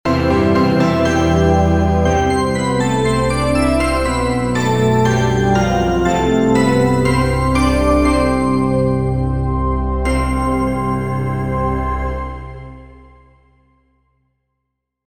9 発車メロディー.mp3